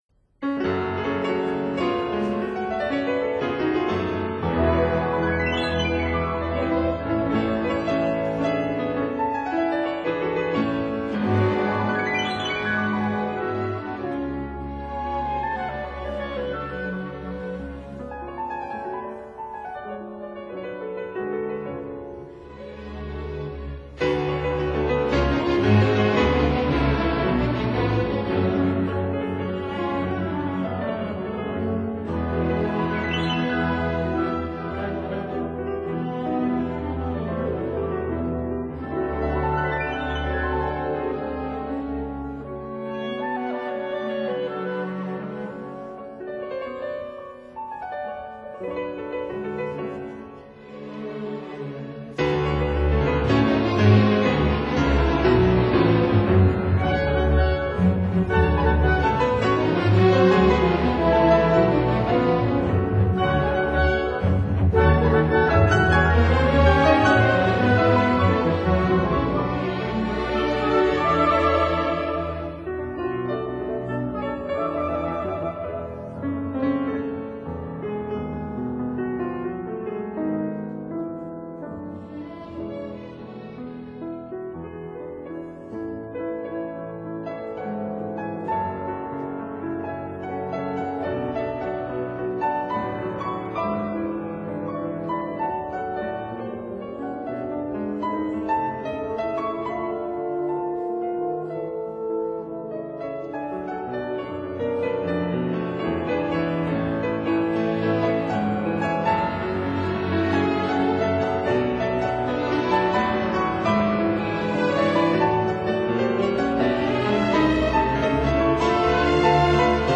Piano Concerto No. 2 in G minor, Op. 16
piano